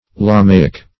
lamaic - definition of lamaic - synonyms, pronunciation, spelling from Free Dictionary
Lamaic \La"ma*ic\, a. Of or pertaining to Lamaism.